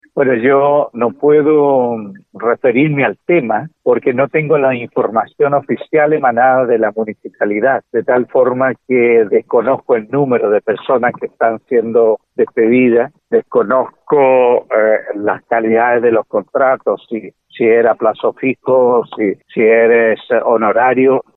Por su parte, el concejal Manuel Jesús Vera, señaló que los antecedentes de los casos aún no han sido entregados, por lo que no se sabe el detalle de los despidos del último mes.